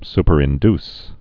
(spər-ĭn-ds, -dys)